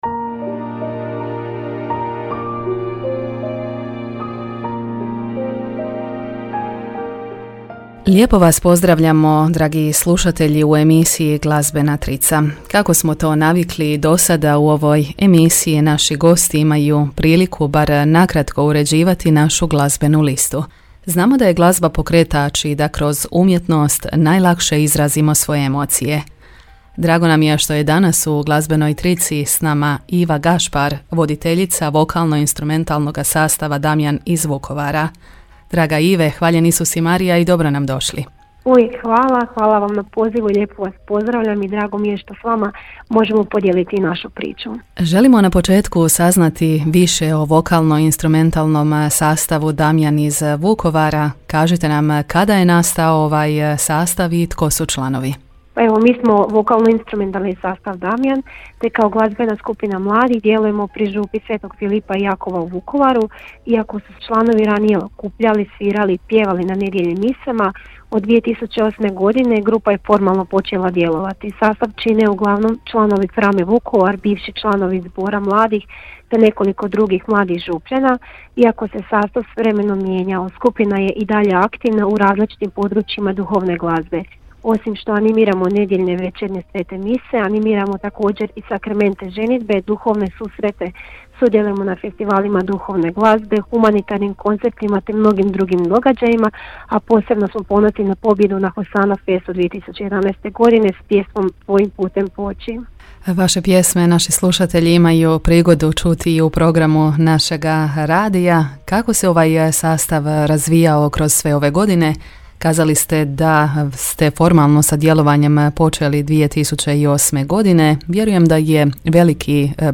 S djelovanjem sastava Damjan upoznala nas je na Veliku srijedu u emisiji Glazbena trica